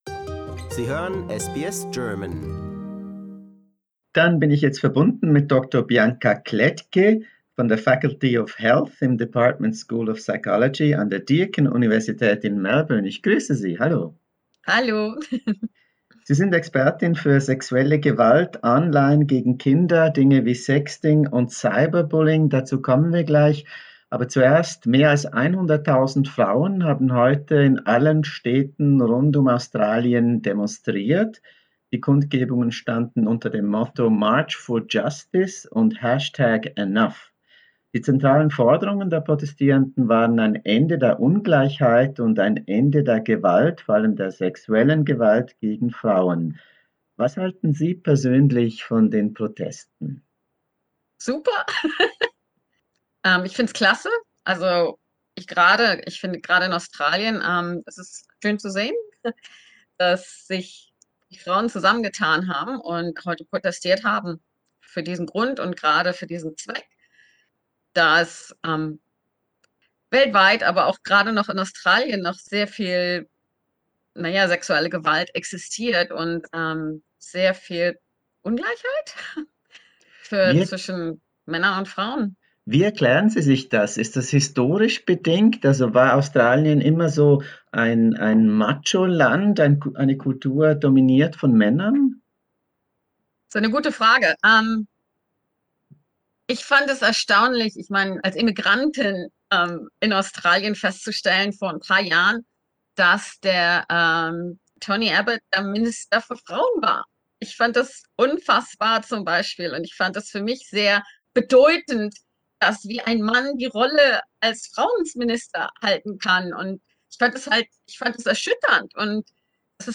SBS-Videointerview